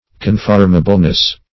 Search Result for " conformableness" : The Collaborative International Dictionary of English v.0.48: Conformableness \Con*form"a*ble*ness\, n. The quality of being conformable; conformability.